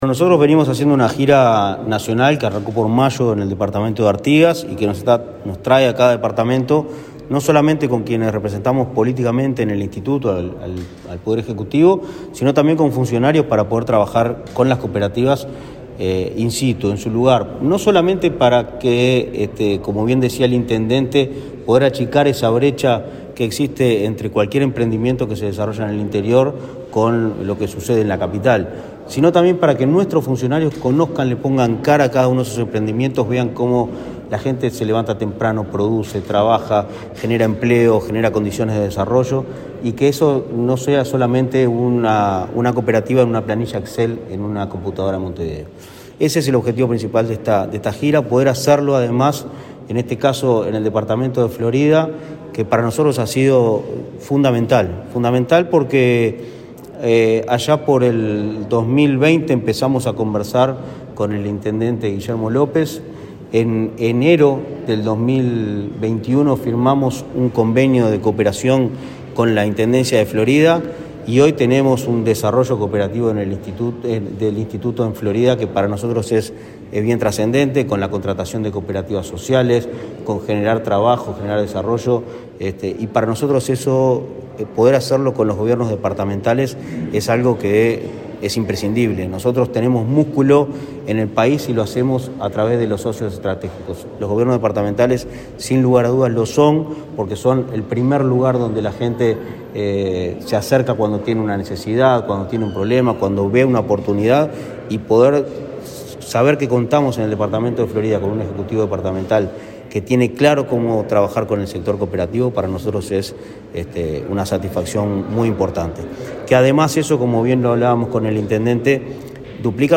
Entrevista al presidente de Inacoop, Martín Fernández
El presidente del Instituto Nacional del Cooperativismo (Inacoop), Martín Fernández, dialogó con Comunicación Presidencial en Florida, donde visitó